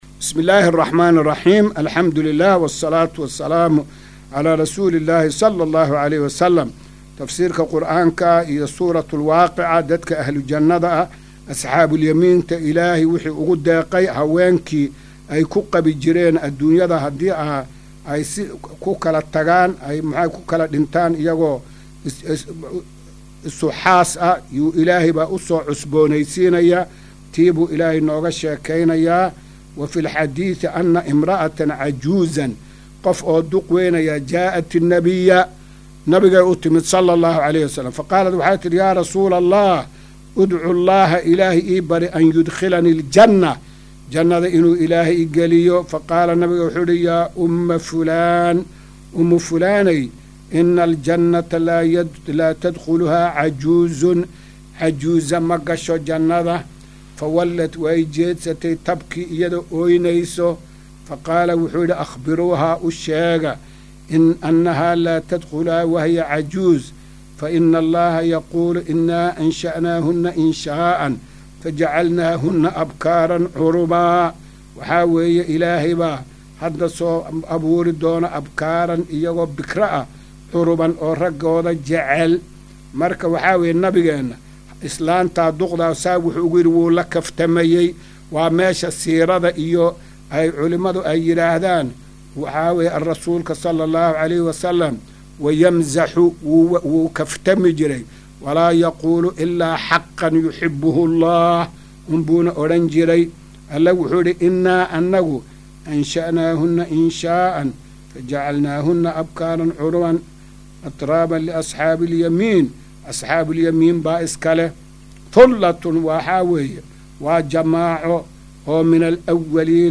Maqal:- Casharka Tafsiirka Qur’aanka Idaacadda Himilo “Darsiga 255aad”